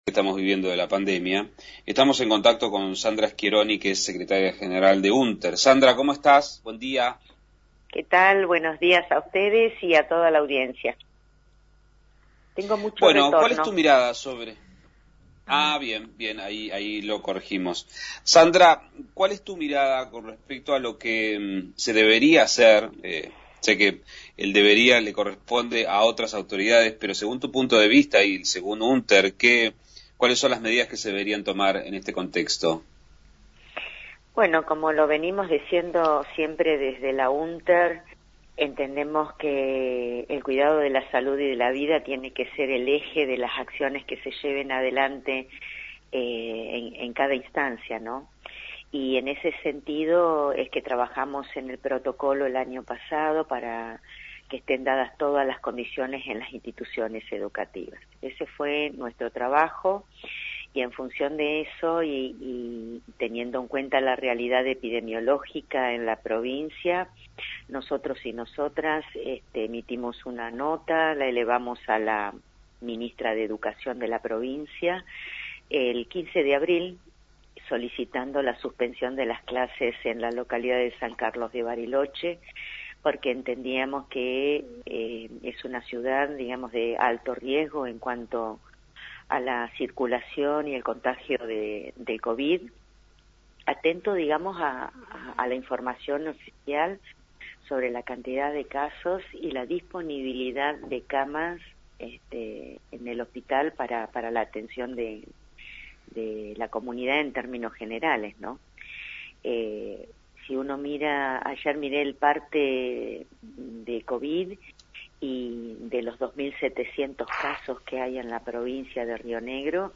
Entrevista
Entrevista en una emisora de Bariloche